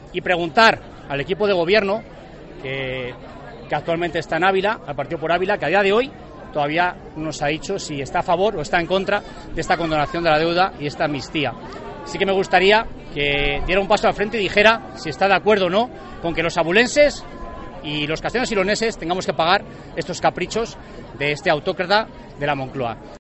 José Antonio Palomo, procurador Vox, pide a Por Ávila que se posicione sobre la amnistía